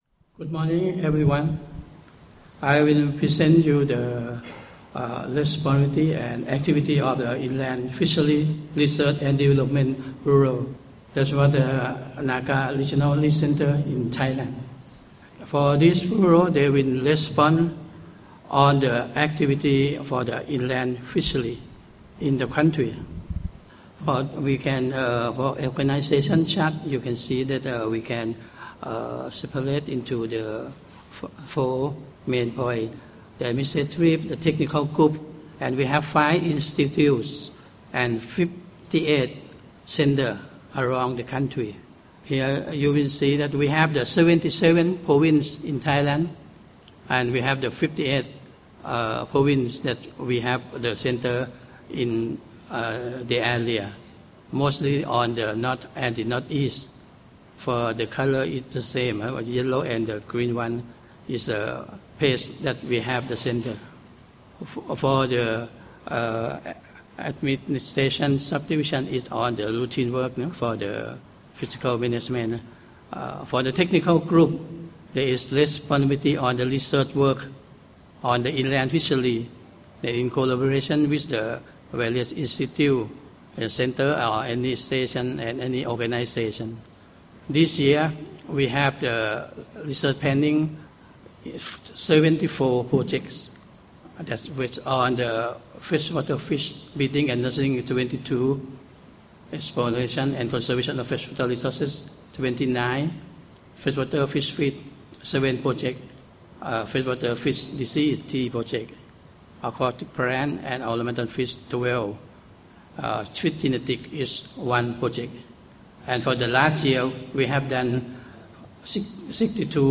The presentations were made at the 23rd NACA Governing Council Meeting, which was held in Siem Reap, 27-29 May 2011, hosted by the Government of Cambodia.